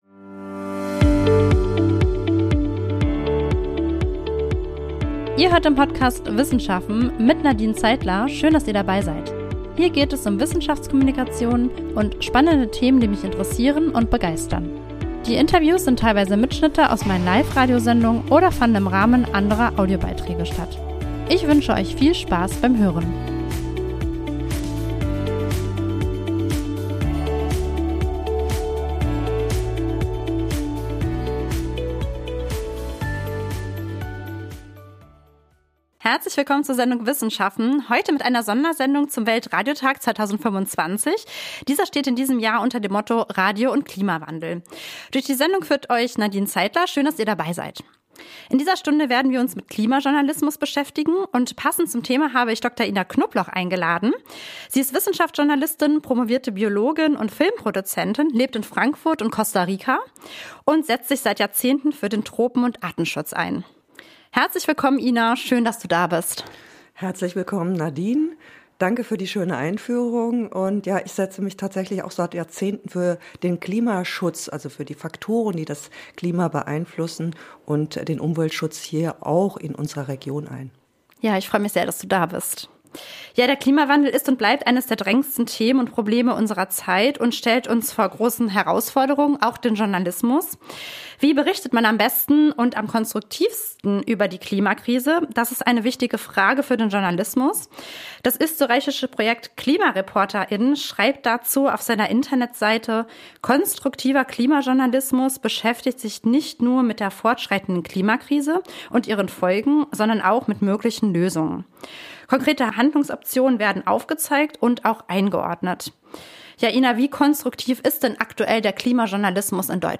Das Interview ist nun als Podcastfolge veröffentlicht. Wie konstruktiv ist aktuell der Klimajournalismus in Deutschland, welchen Platz nimmt die Klimakrise in den Medien ein und was braucht es für eine gute, wissenschaftsfundierte Klimaberichterstattung?